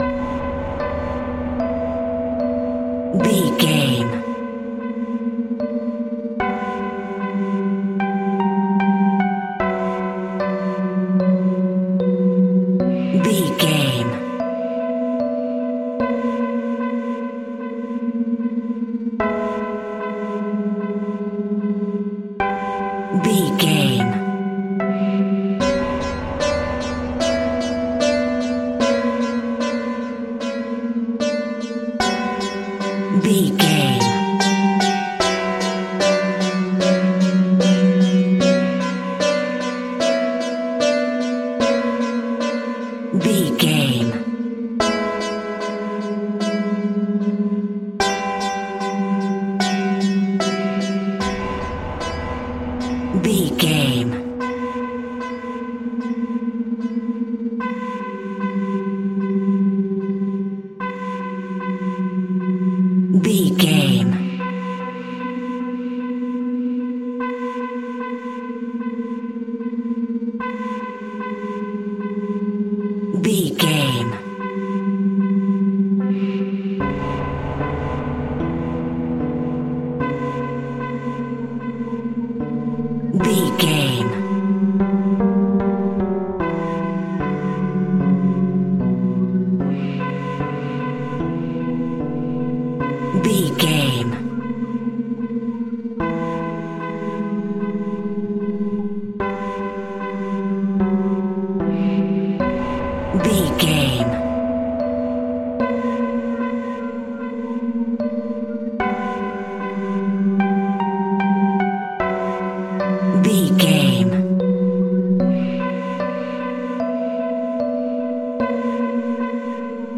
Aeolian/Minor
tension
ominous
dark
suspense
eerie
synthesizer
strings
Synth Pads
atmospheres